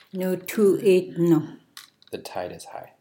Tide /nutu…dun/